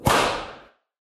whip-sound-effect-2.mp3